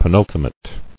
[pe·nul·ti·mate]